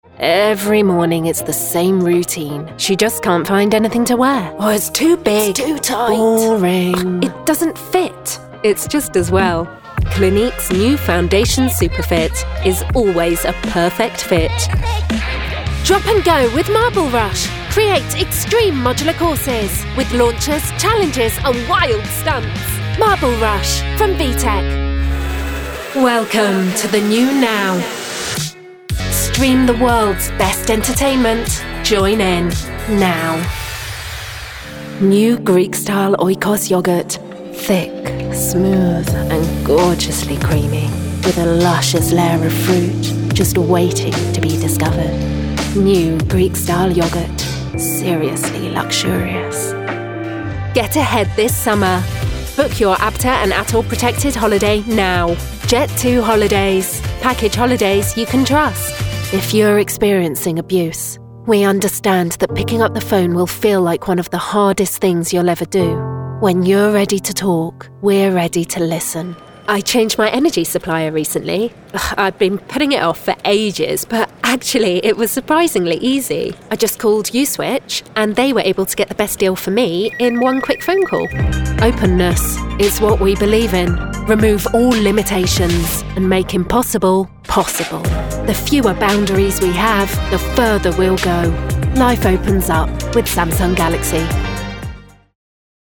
Anglais (britannique)
Brillant
Pétillant
Lisse